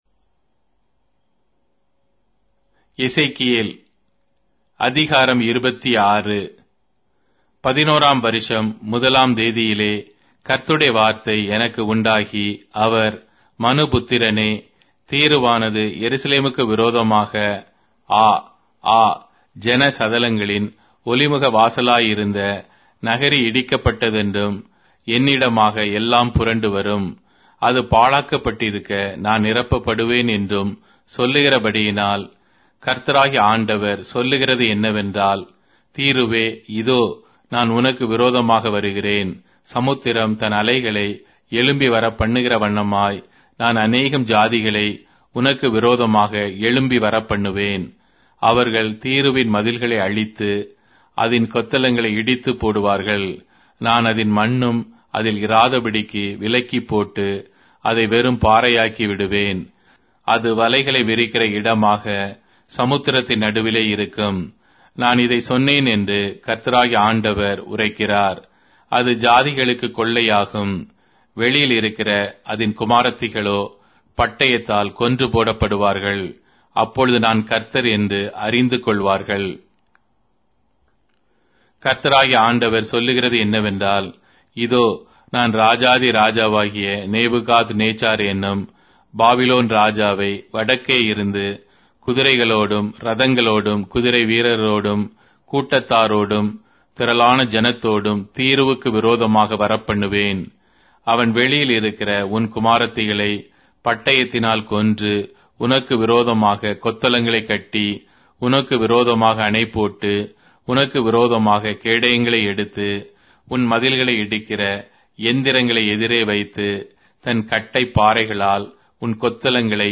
Tamil Audio Bible - Ezekiel 8 in Tov bible version